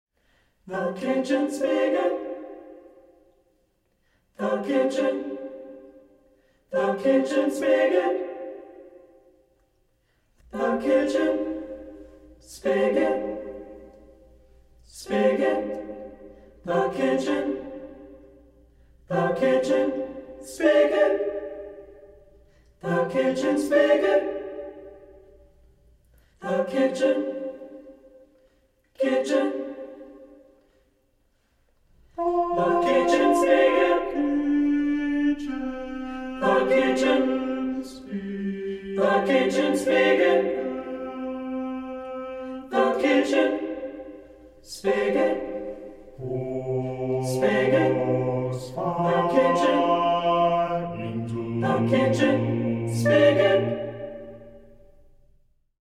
• Genres: Choral Music